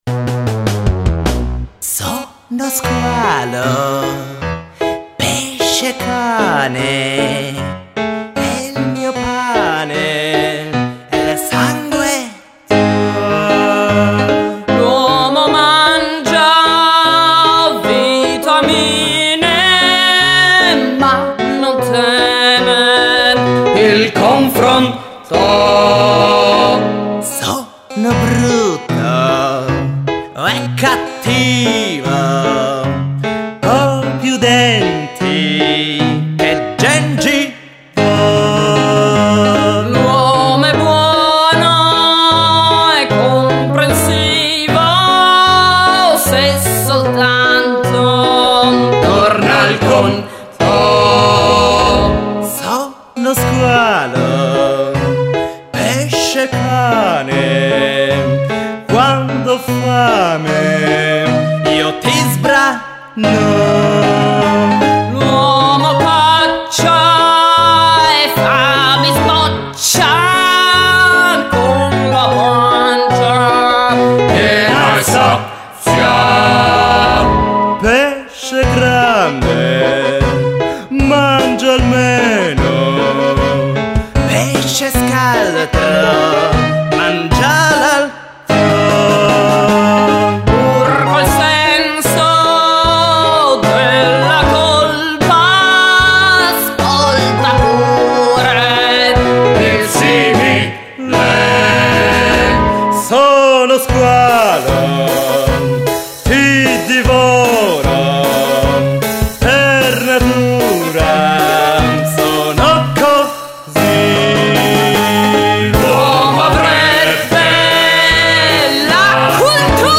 spettacolo musicale tragicomico (come tutto è)